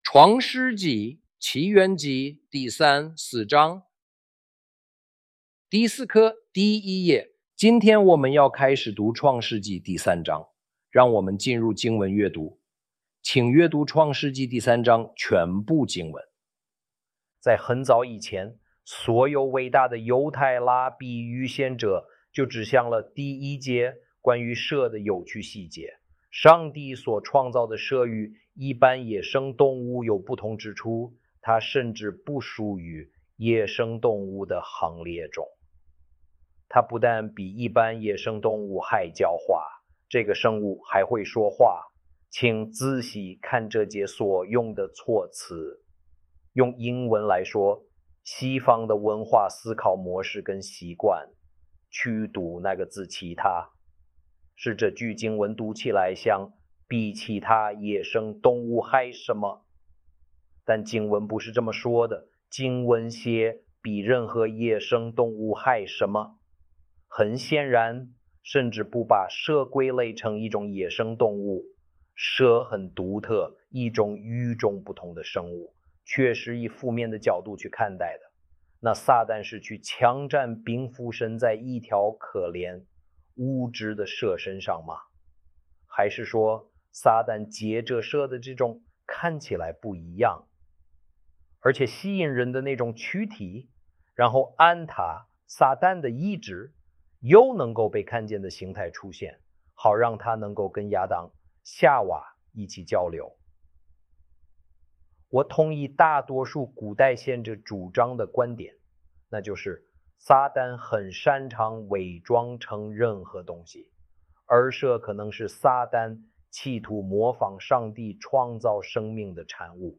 第四課 - 創世記第三章和第四章 Dì sì kè - chuàngshì jì dì sān zhāng hé dì sì zhāng - Torah Class
zh-audio-genesis-lesson-4-ch3-ch4.mp3